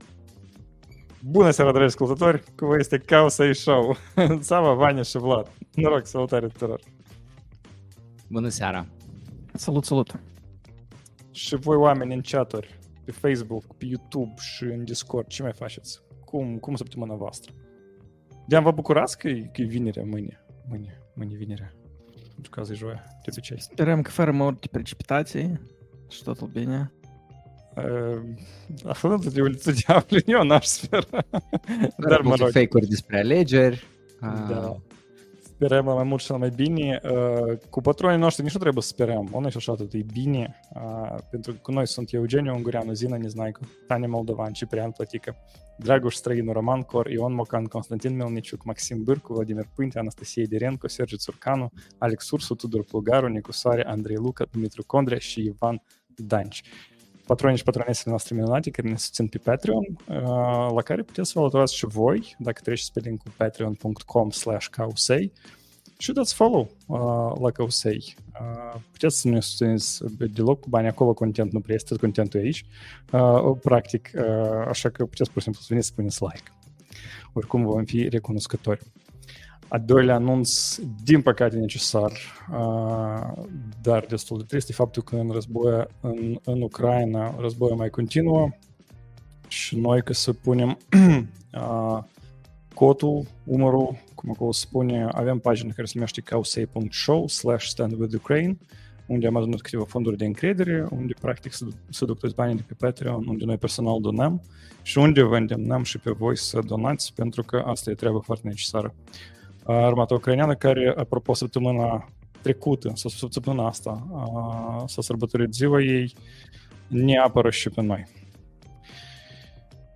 #123: AI clonează AI, "Deschideți ușa" (Manager Tesla), Cobol ca Sofia Rotaru October 10th, 2024 Live-ul săptămânal Cowsay Show.